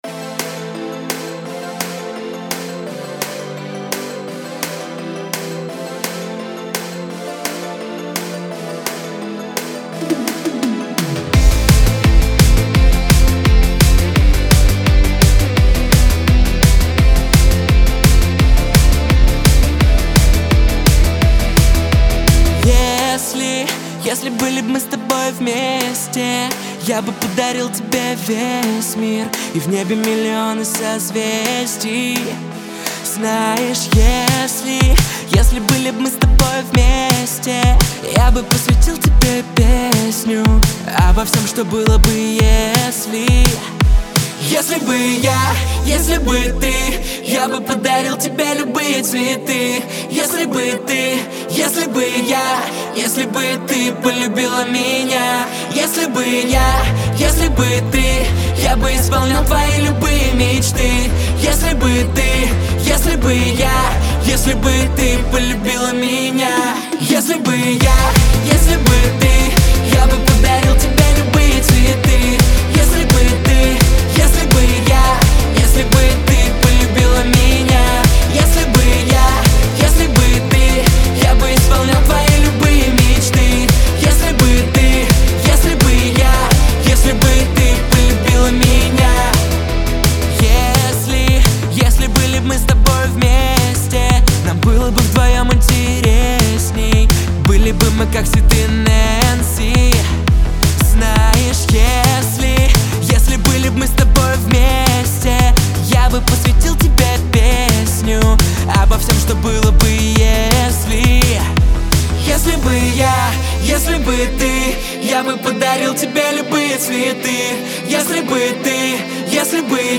который сочетает в себе элементы поп и инди-музыки.